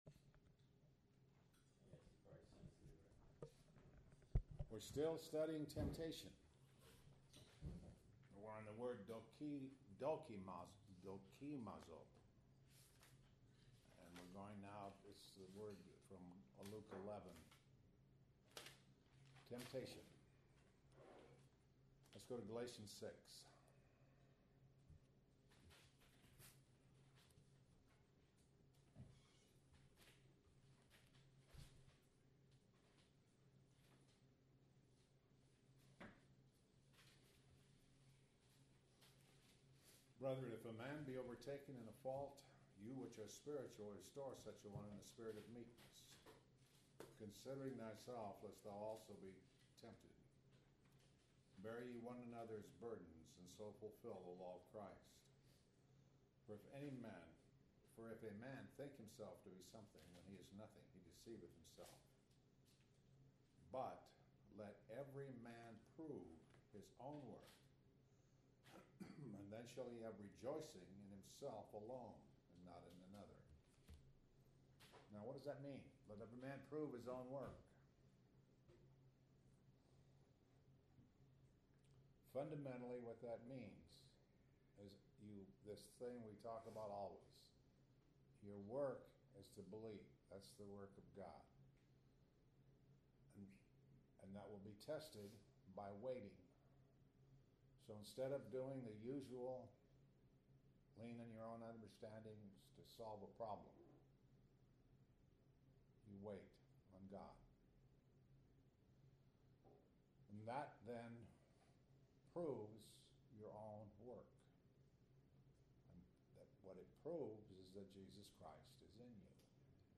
← Luke 11:4 – January 9, 2013 Luke 11:4 – January 14, 2013 → Luke 11:4 – January 10, 2013 Posted on May 4, 2013 by admin Luke 11:4 – January 10, 2013 This entry was posted in Morning Bible Studies .